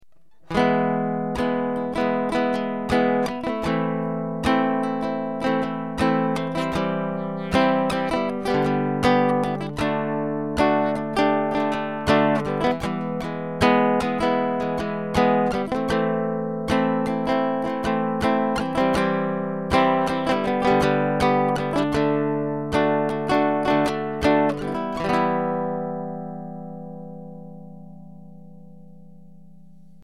Heute habe ich meine neue Bariton-Ukulele gespielt und über den kleinen Digitalmixer mit einem optischen S/PDIF -> USB-Konverter auf meinem Handy aufgenommen.
ukulele01.mp3